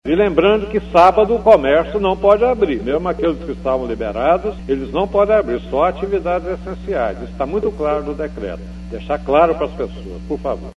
áudio exibido na Rádio Educadora AM/FM Ubá-MG